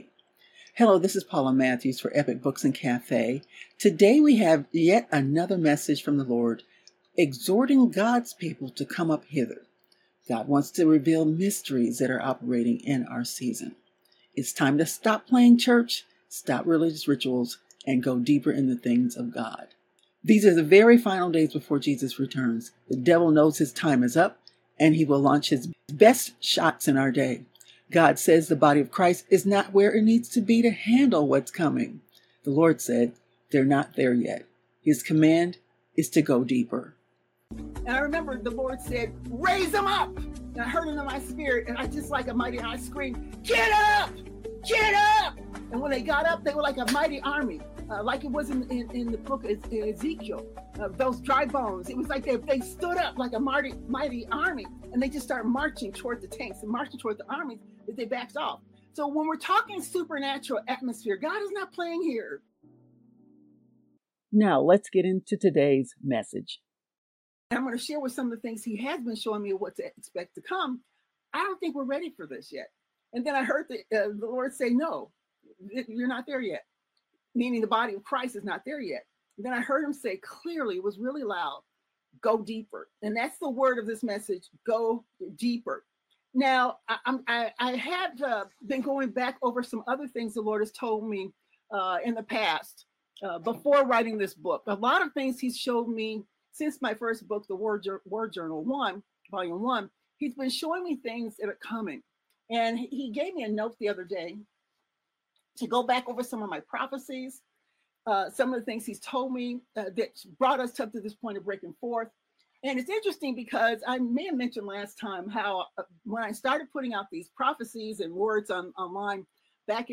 The answers are shrouded in supernatural mysteries which will only be revealed to those who “Go Deeper” with God. This message is taken from the August 24, 2025 live broadcast of In Pursuit Of Divine Destiny on YouTube, entitled, “ Go Deeper Into The Things Of God. ”